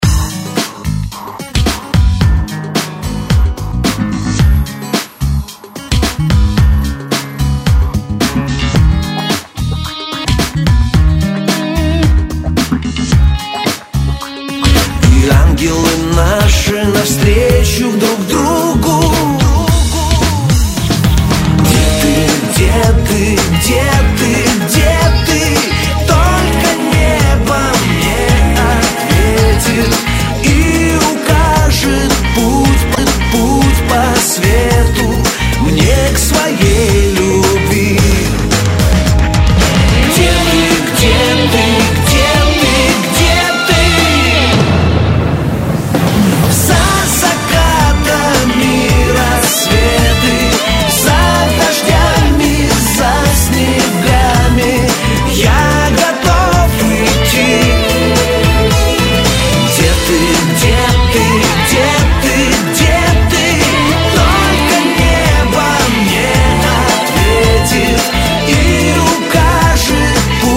из Шансон
Категория - шансон.